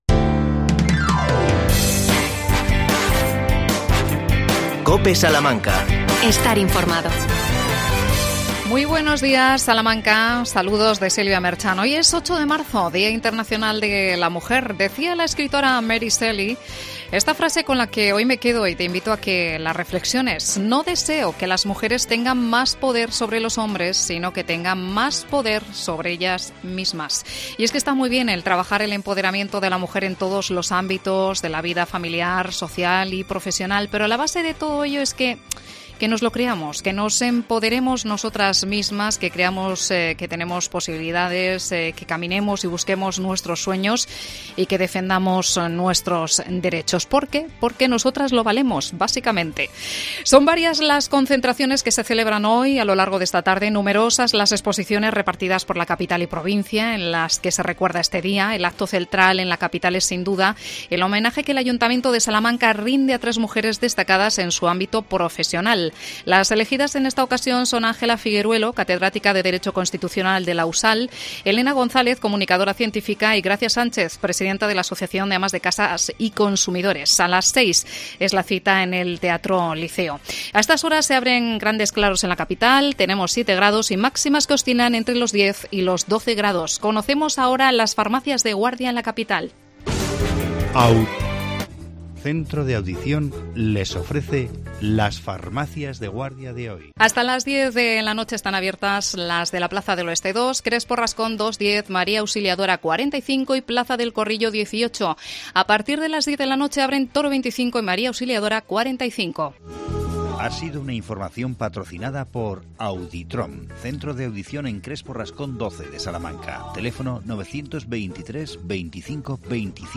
AUDIO: Entrevista a Isabel Macías, concejala de Mayores. El tema: nuevo centro en Chamberí.